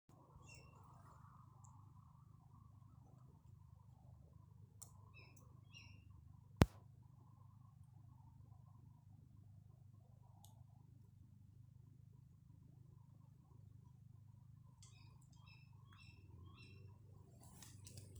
Птицы -> Дятловые ->
седой дятел, Picus canus
Ziņotāja saglabāts vietas nosaukumsVecumnieku pag